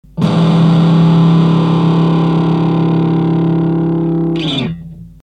音が出た。